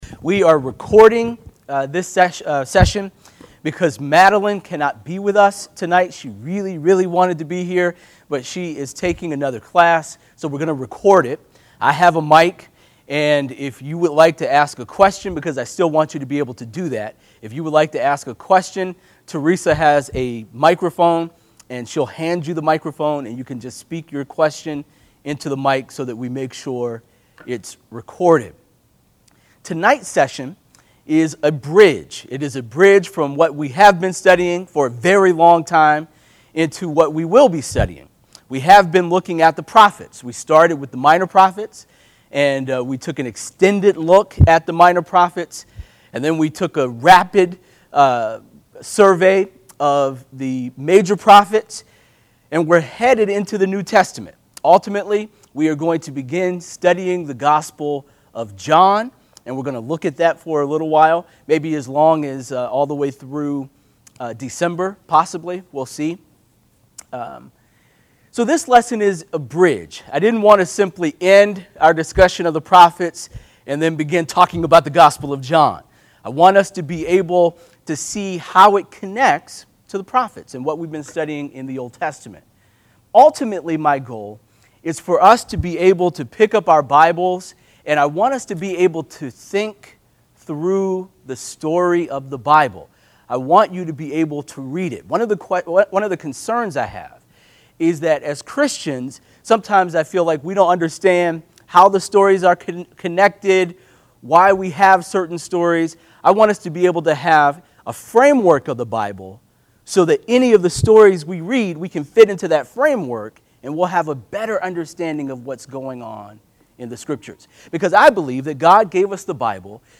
This is a recording of a Wednesday night bible study, not a Sunday sermon